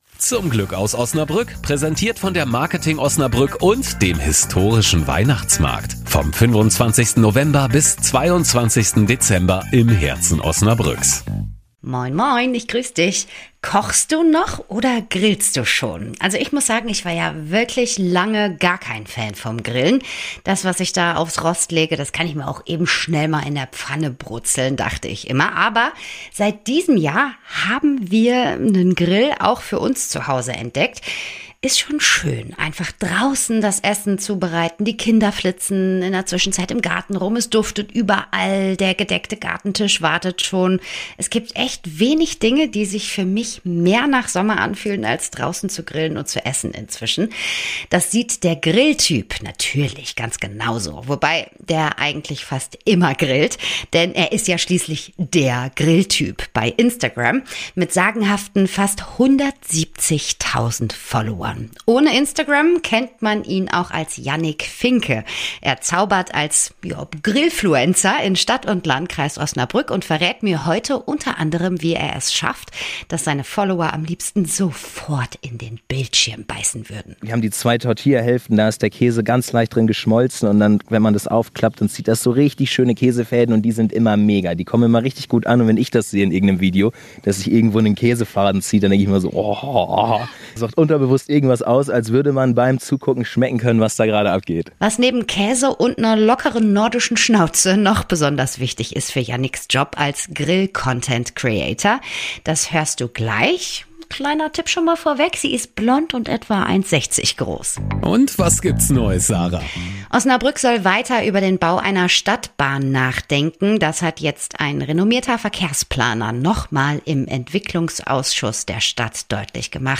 Während alles auf der Feuerplatte brutzelt, würde ich mich am liebsten selbst direkt mit Kräuterbutter bestreichen und mit drauf hüpfen.